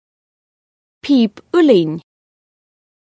Amazon AWS ( Scottish [!] pronunciation) .